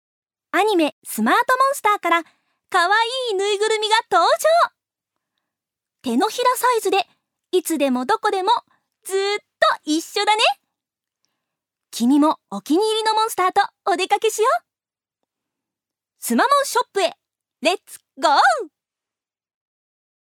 女性タレント
音声サンプル
ナレーション３